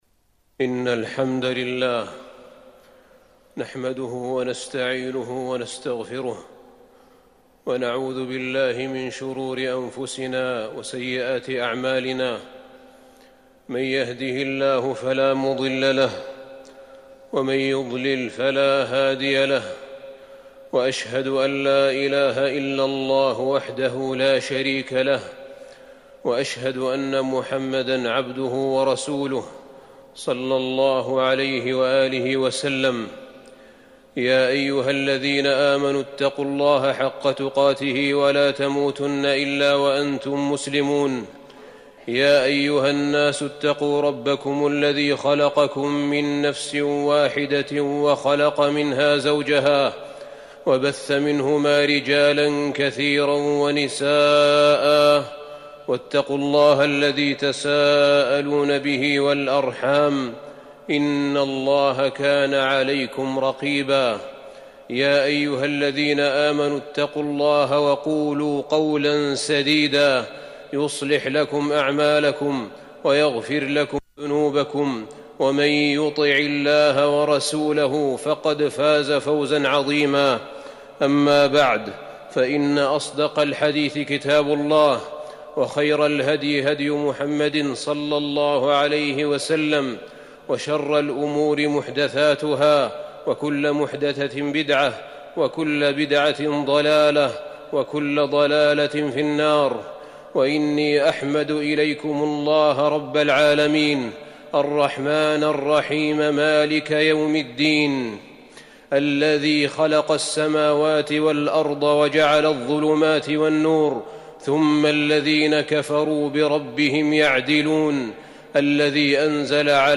تاريخ النشر ٦ شوال ١٤٤١ هـ المكان: المسجد النبوي الشيخ: فضيلة الشيخ أحمد بن طالب بن حميد فضيلة الشيخ أحمد بن طالب بن حميد وقل الحمد لله